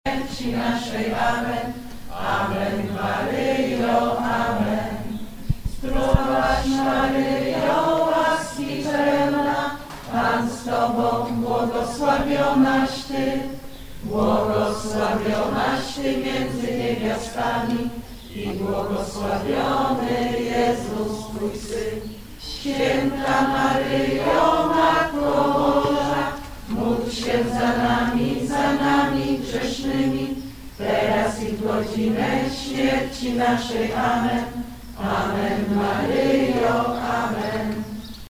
W sobotę o godzinie 10:00 rozpoczął się Zjazd Parafialnych Zespołów Caritas Archidiecezji Wrocławskiej.
Około godz. 11:30 wolontariusze z modlitwą na ustach wyruszyli przed obraz Matki Boskiej Adorującej.
rozaniec.mp3